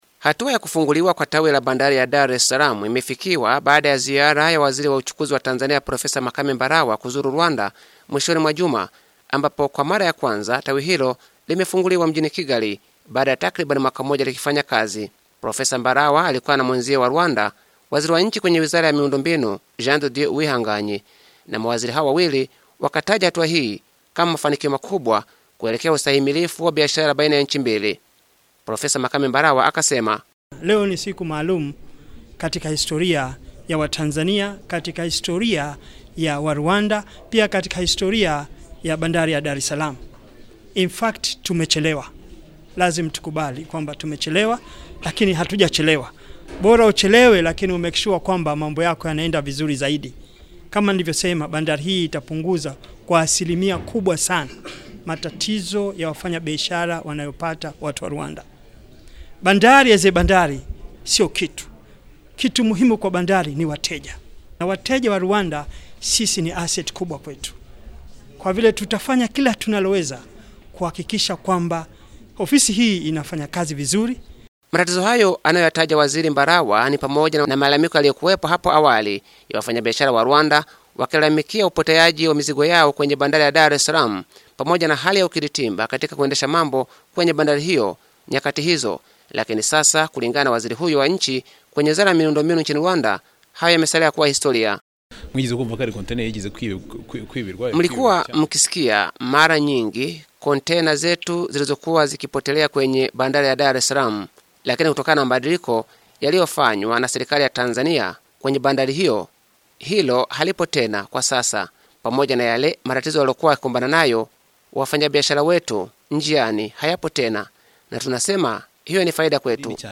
Habari / Afrika